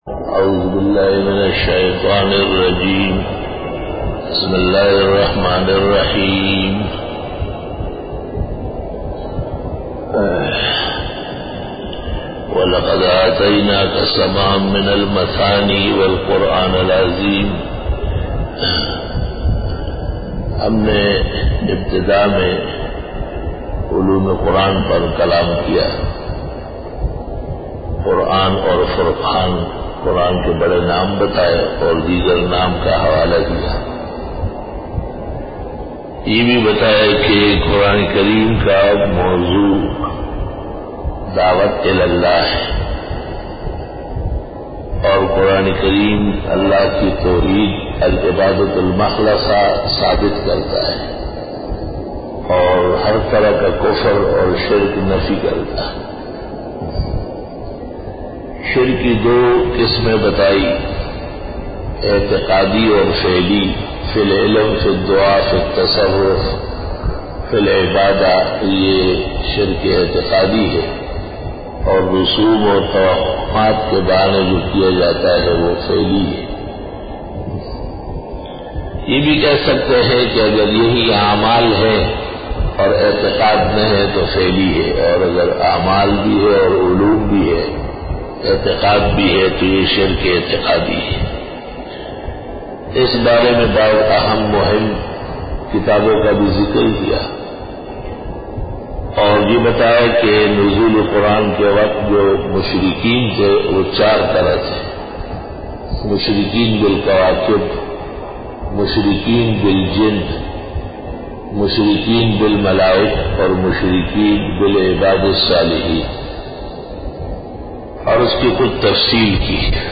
دورہ تفسیر | دن 06 |2014 Bayan